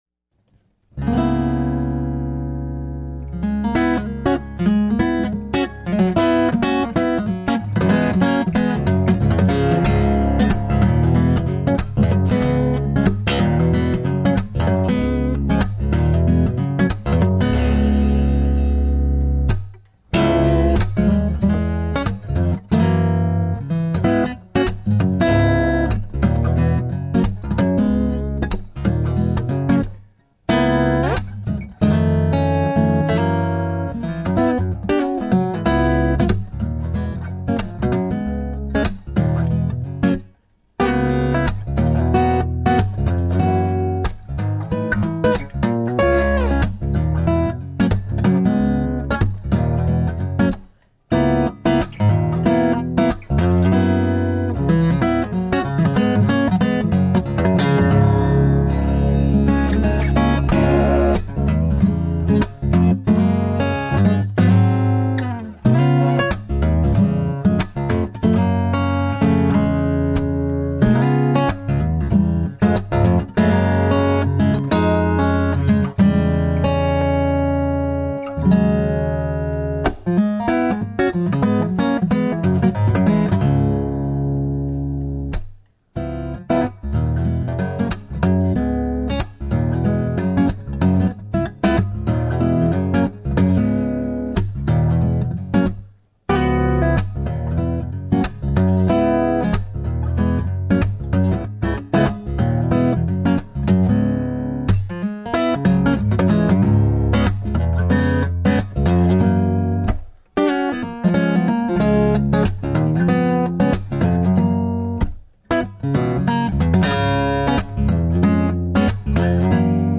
Here are a few samples of my guitar playing: (I plan to add more pictures along with some clips from old bands when I can get time to go through my stored memorabilia)
Acoustic Fingerstyle - Jamie's Tattoo (original) An early original tune I made up soon after I started playing acoustic fingerstyle.